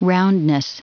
Prononciation du mot : roundness